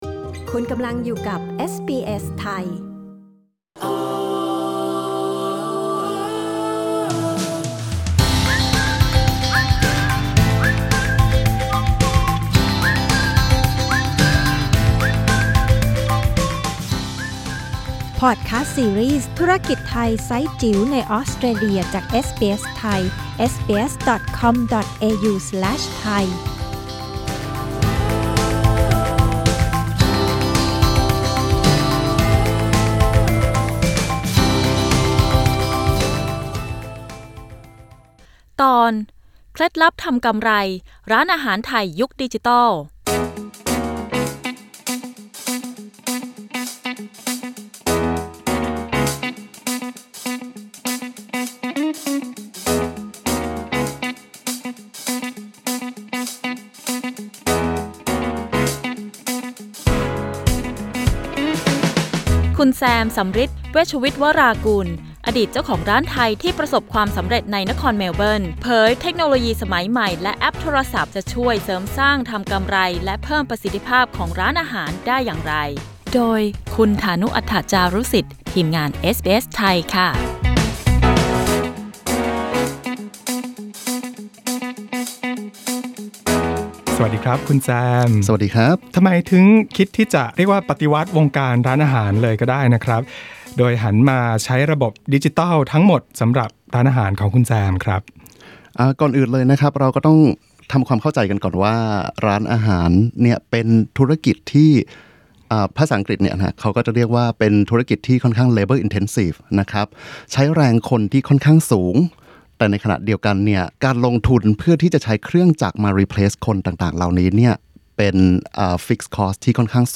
กดปุ่ม 🔊 ที่ภาพด้านบนเพื่อฟังสัมภาษณ์เรื่องนี้